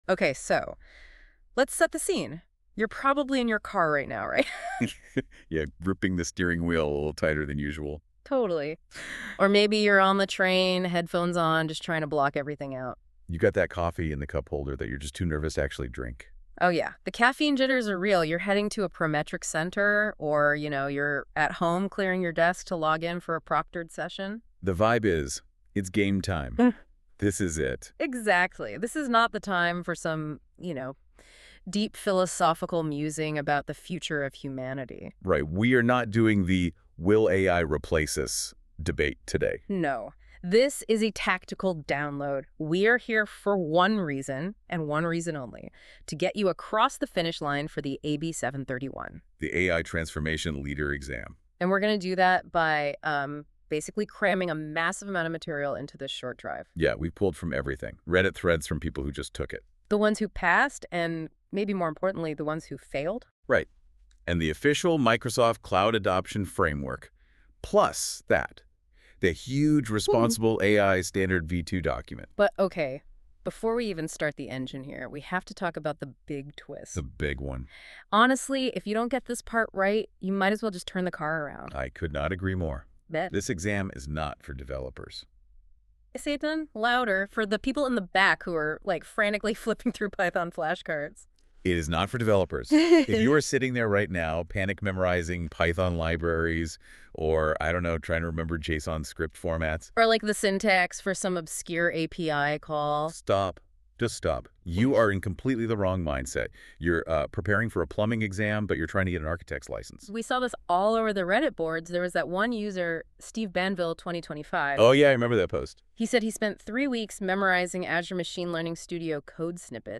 ✨ Generated by NotebookLM
A podcast-style walkthrough of key exam tactics.